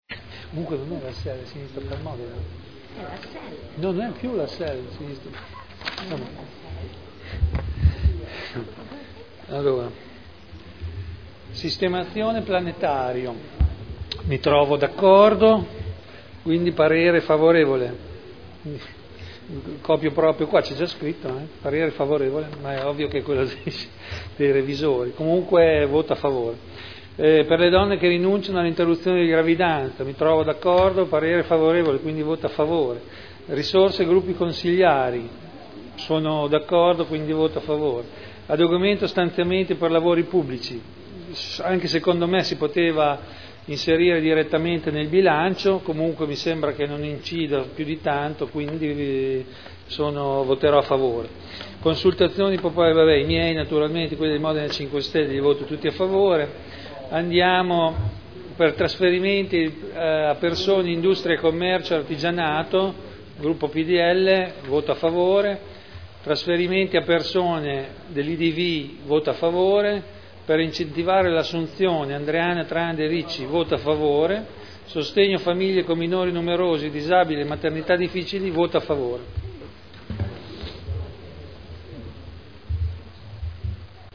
Seduta del 28/03/2011. Dichiarazioni di voto su emendamenti.